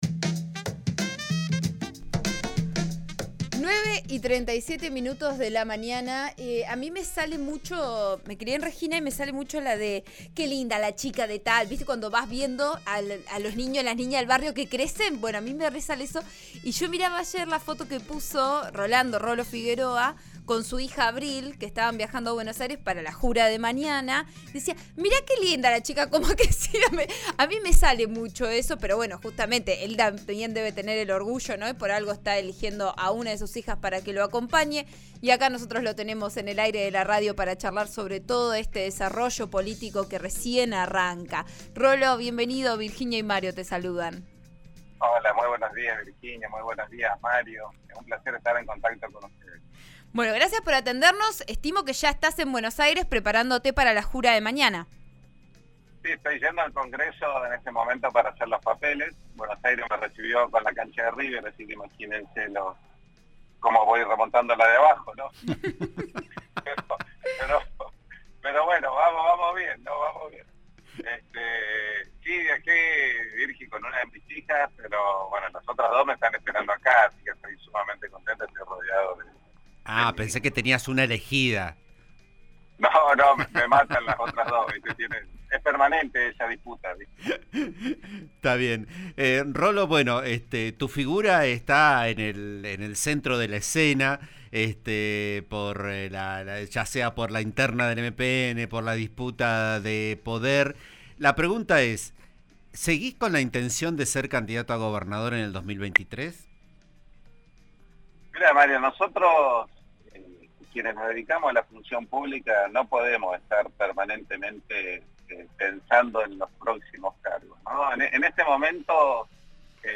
En declaraciones a RN RADIO (89.3) habló sobre su futuro como legislador nacional, sus proyectos, sobre el Movimiento Popular Neuquino.